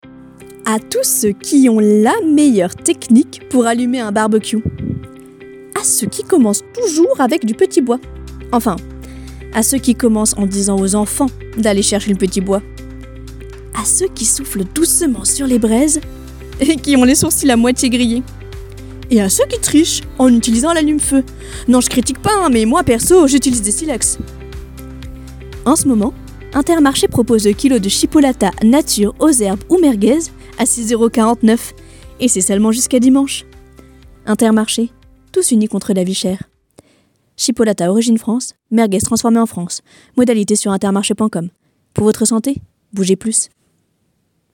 Voix off
maquette pub intermarché